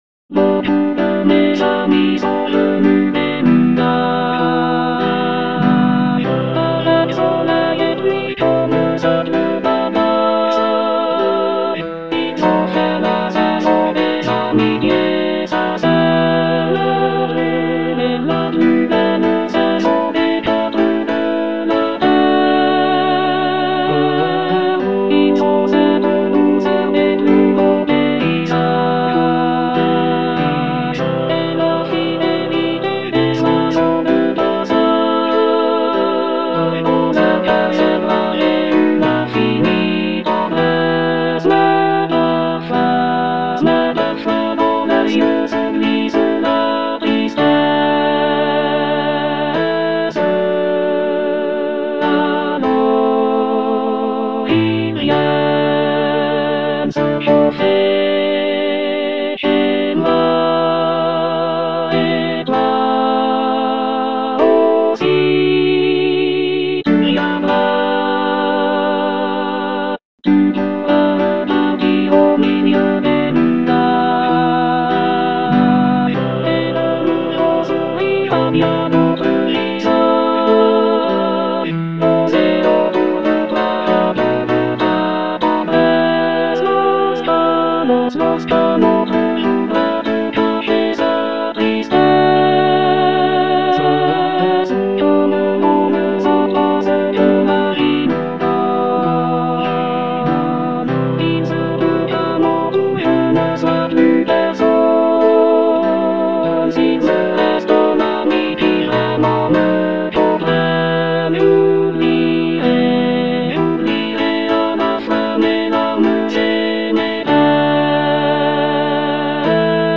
Alto II (Emphasised voice and other voices)
piece for choir